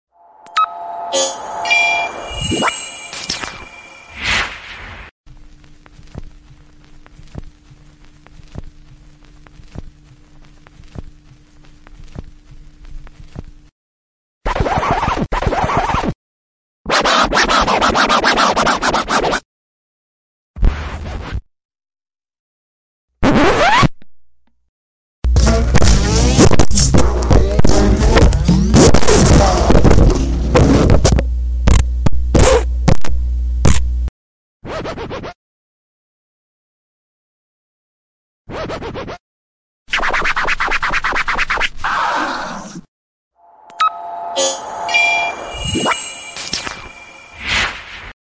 Vinyl Record Scratch Sound Reel sound effects free download